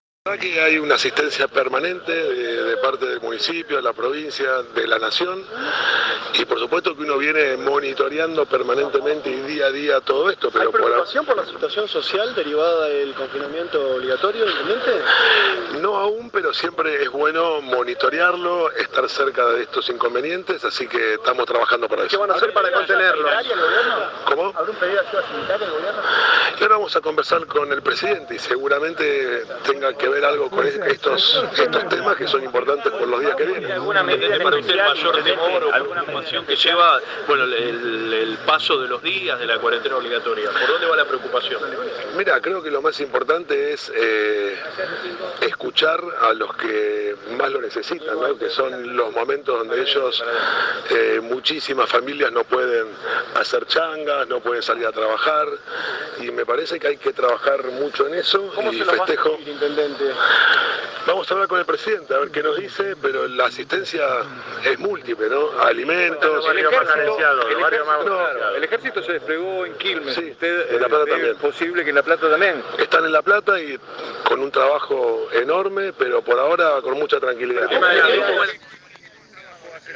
Previo al encuentro, y en contacto con la prensa, Garro indicó que en La Plata por el momento "no hubo ningún caso ni lo hay", aunque admitió que "hay que prepararse porque esto seguramente sea largo y muy difícil".